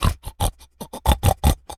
pig_sniff_01.wav